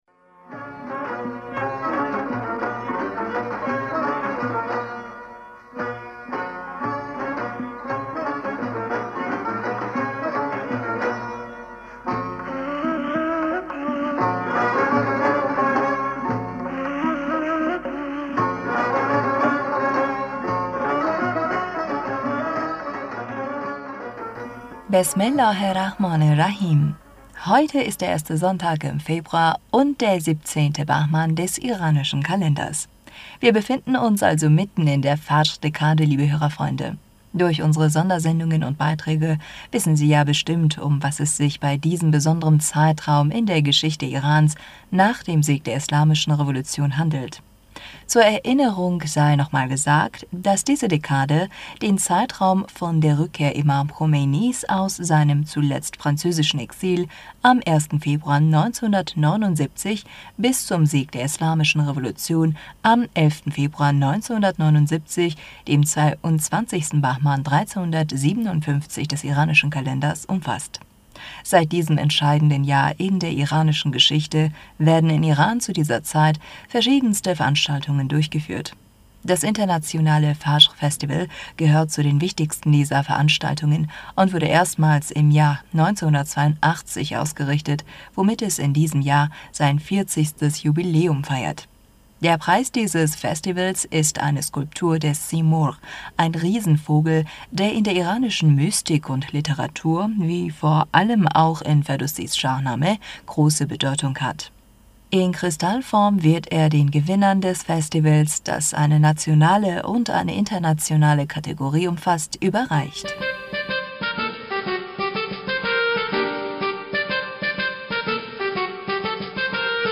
Hörerpostsendung am 06. Februar 2022 Bismillaher rahmaner rahim Heute ist der erste Sonntag im Februar, und der 17.Bahman des iranischen Kalende...